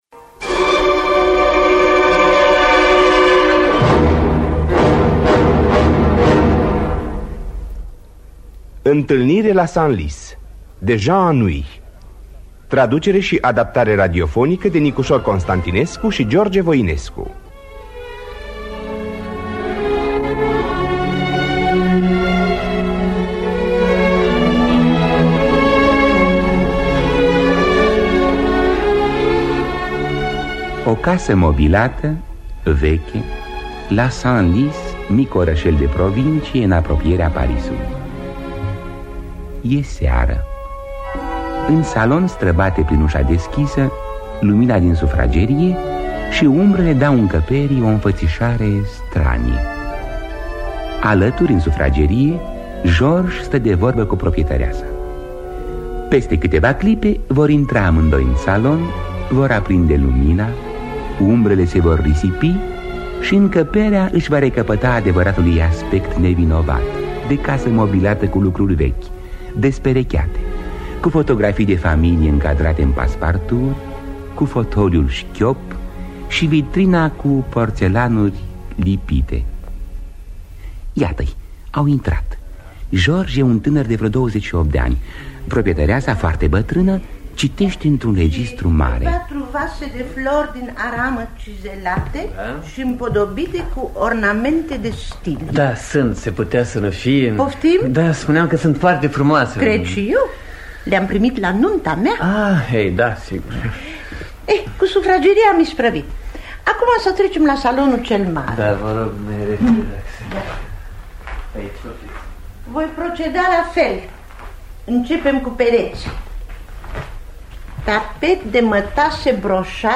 Jean Anouilh – Intalnire La Senlis (1957) – Teatru Radiofonic Online